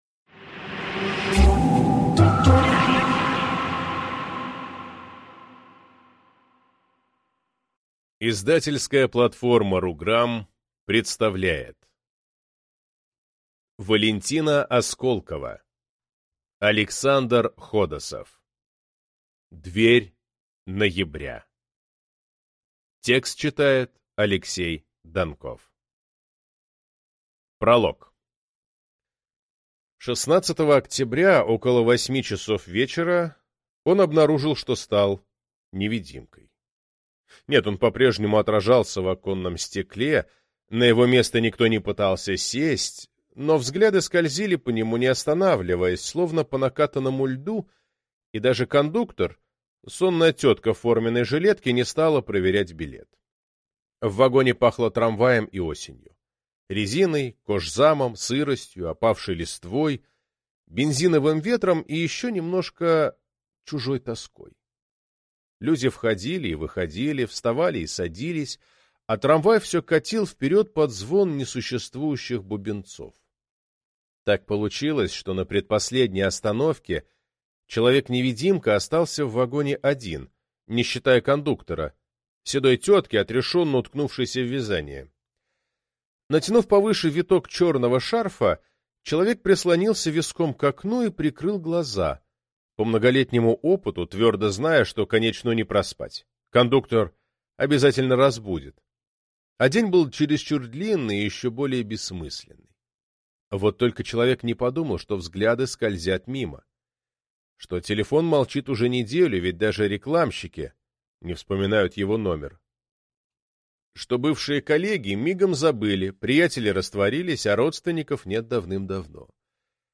Аудиокнига Дверь ноября | Библиотека аудиокниг